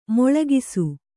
♪ moḷagisu